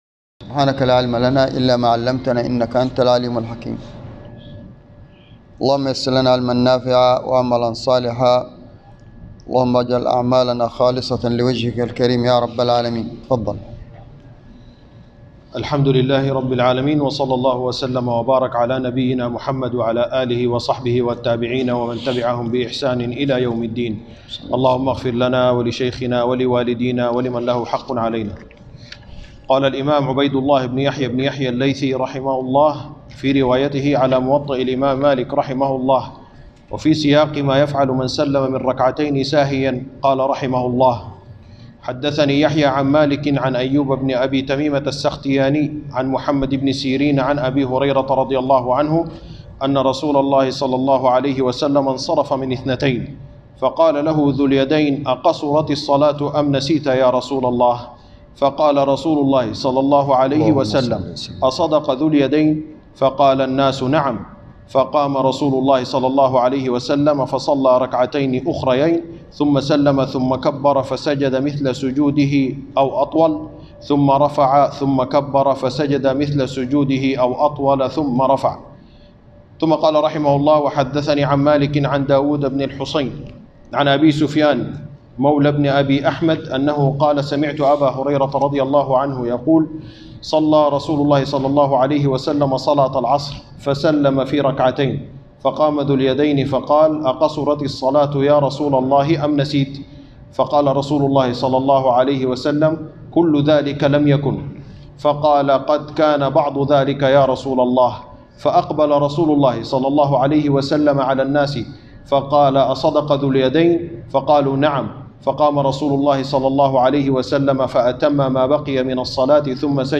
الدرس الرابع عشر من كتاب الصلاة - موطأ الإمام مالك _ 14